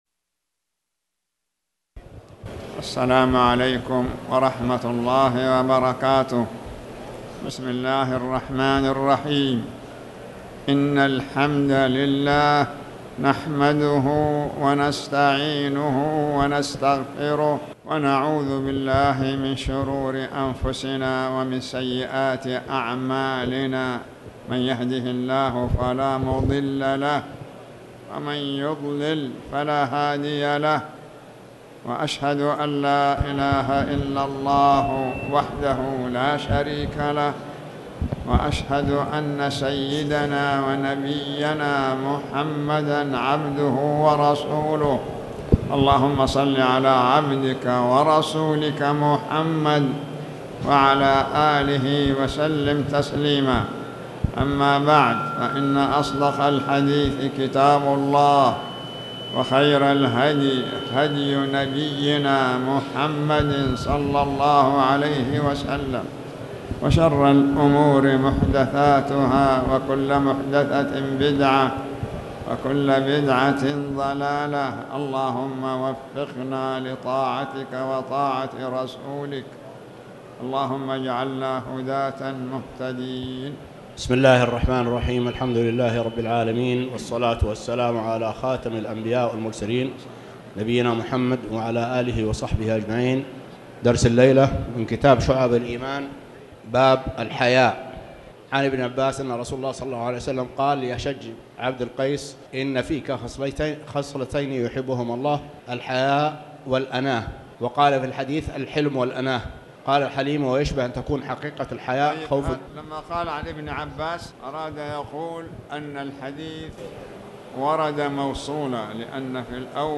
تاريخ النشر ١٣ ربيع الثاني ١٤٣٩ هـ المكان: المسجد الحرام الشيخ